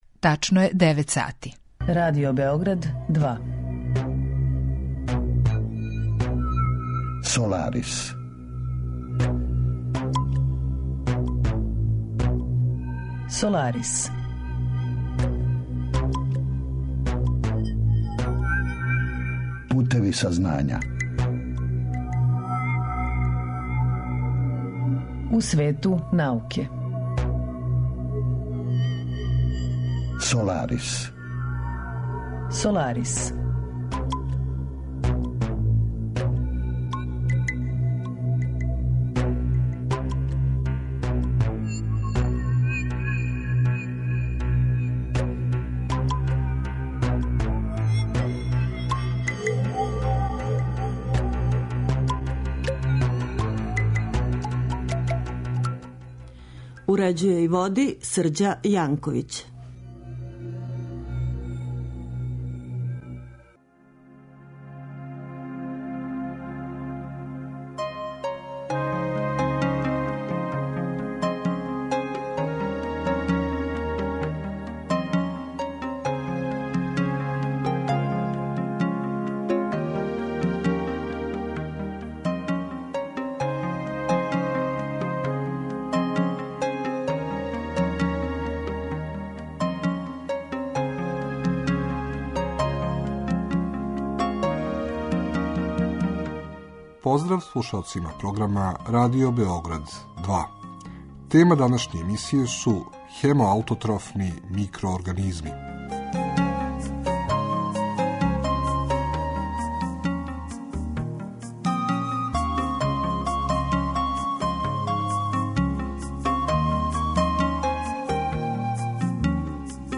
Разговор је први пут емитован 6. јануара 2016.